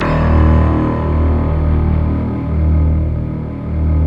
SI1 PLUCK01R.wav